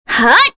Kirze-Vox_Attack4_kr.wav